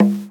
cch_09_percussion_one_shot_conga_low_kong.wav